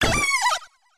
se_queja2.wav